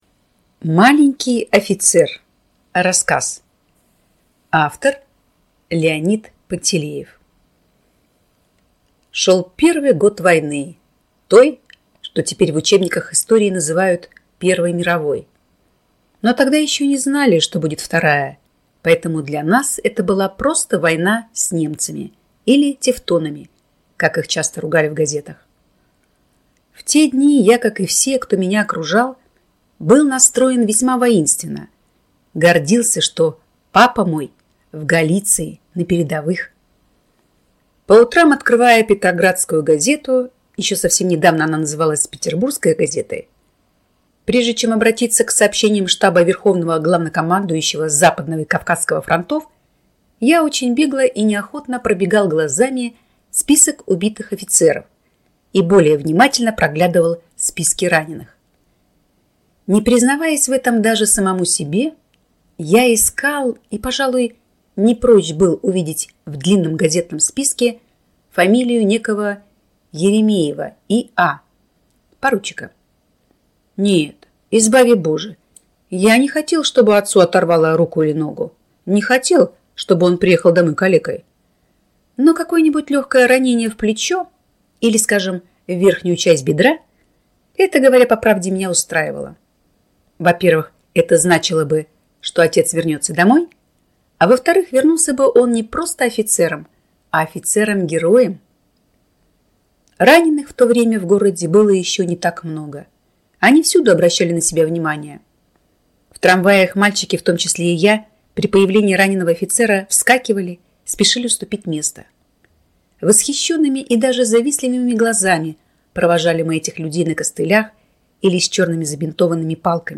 Маленький офицер - аудио рассказ Пантелеева - слушать онлайн